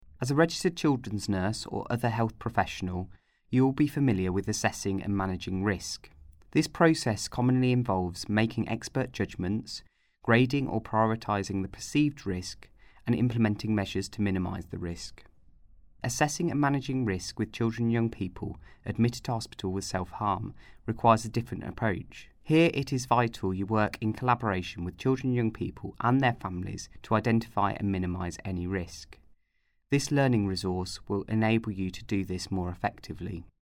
Narration audio (MP3) Narration audio (OGG) Contents Home Introduction What is Risk Assessment and Management?